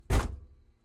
Mazda_RX7_t12_Var_SFX_Door_Close_Interior_DPA4021.ogg